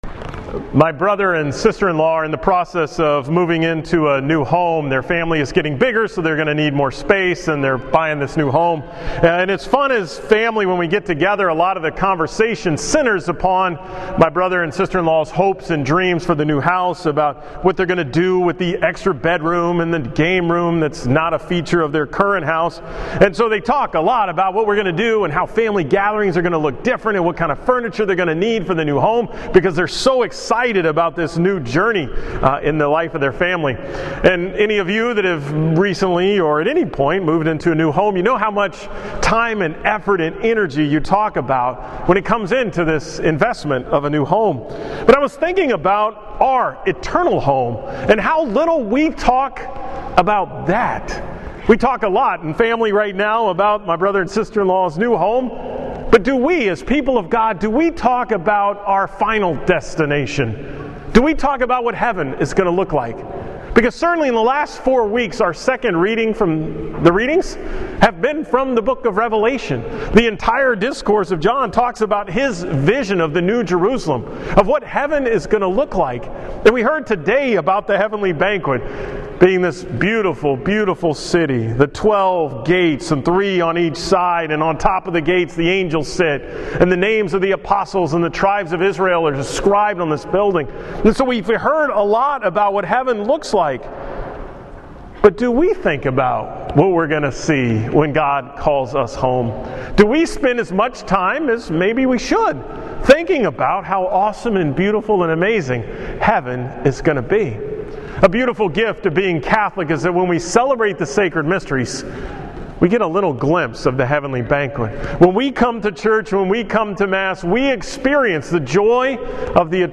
From Mass at St. Michael's on May 1, 2016